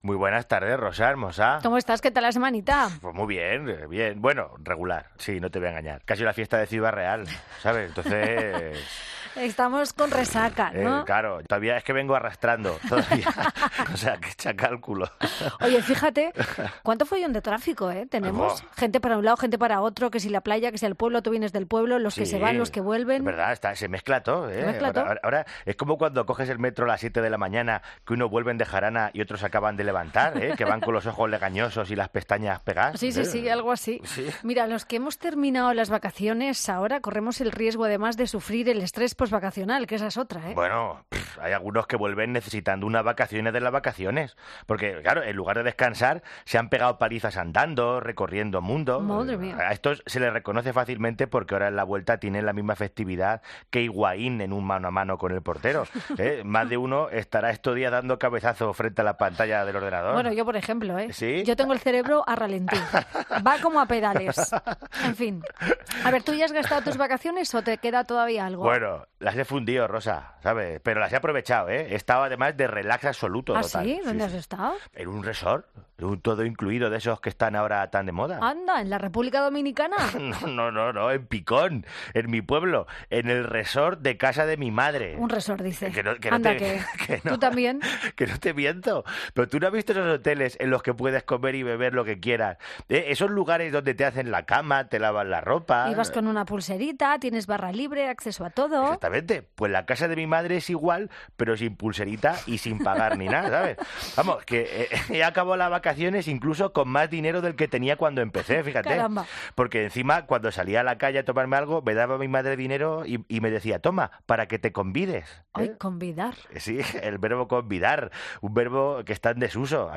Piano en mano, lógicamente.